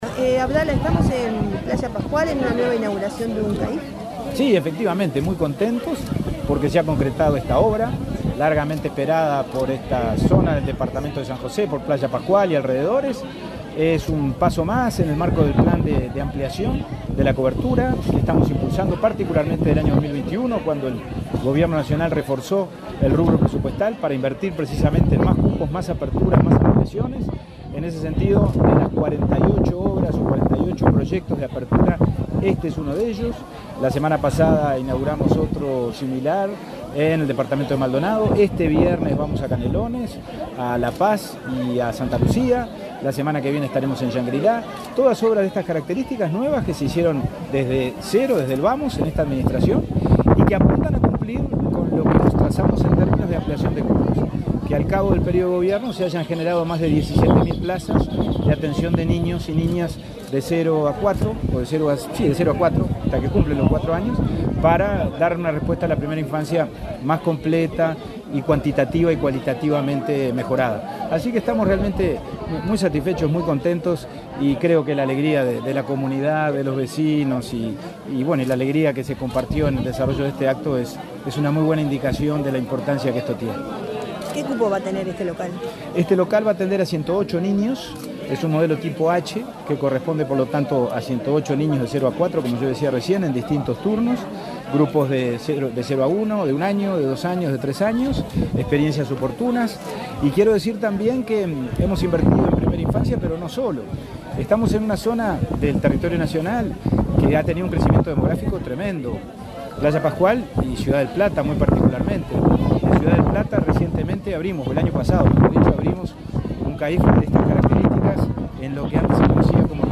Entrevista al presidente del INAU, Pablo Abdala
Entrevista al presidente del INAU, Pablo Abdala 19/09/2023 Compartir Facebook Twitter Copiar enlace WhatsApp LinkedIn El presidente del Instituto del Niño y el Adolescente del Uruguay (INAU), Pablo Abdala, dialogó con Comunicación Presidencial en San José, durante la inauguración del centro de atención a la infancia y la familia (CAIF) Kunumi en Playa Pascual.